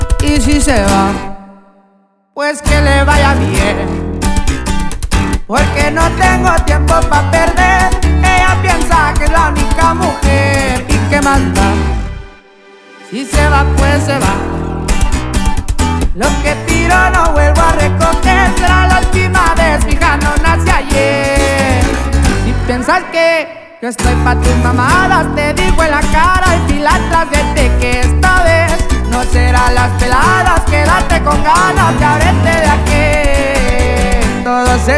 # Regional Mexican